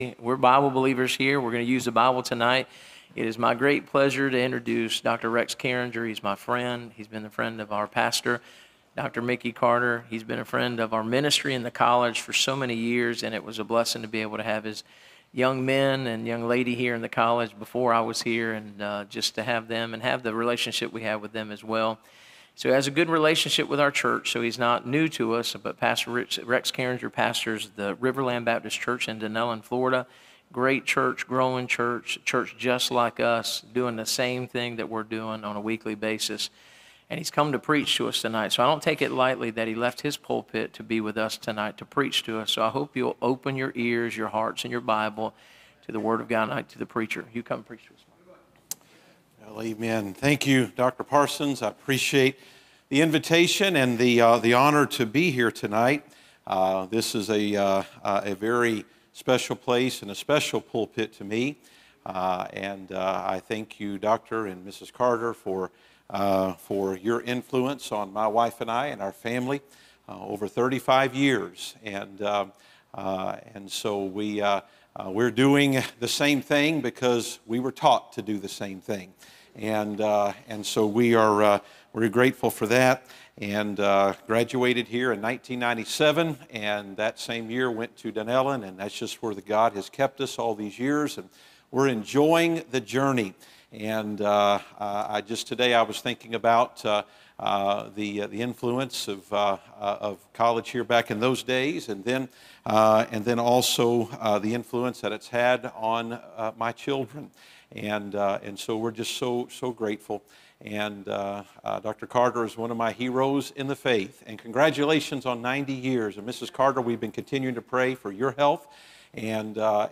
Series: Back To School Revival 2025
Preacher